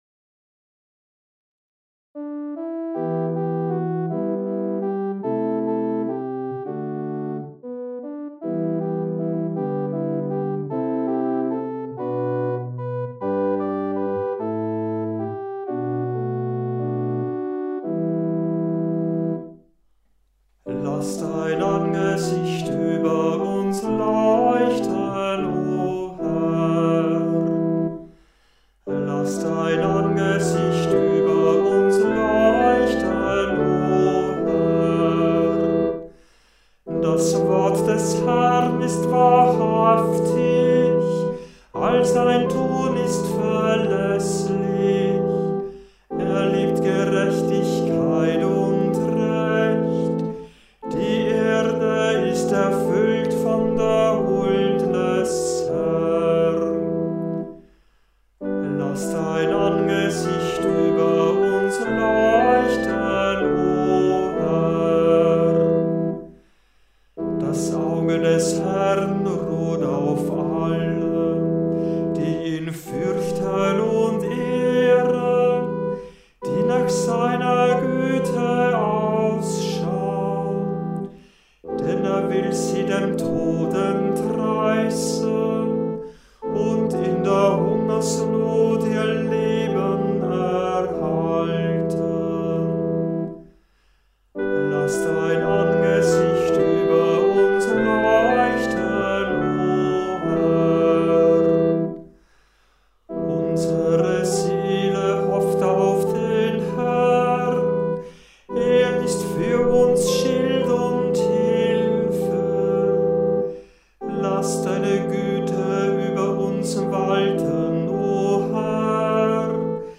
Hörbeispiele aus verschiedenen Kantorenbüchern